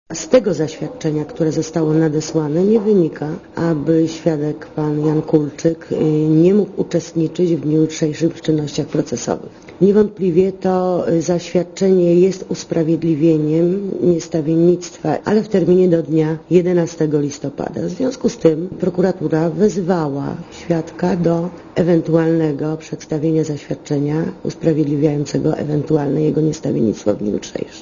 Posłuchaj komentarza rzeczniczki prokuratury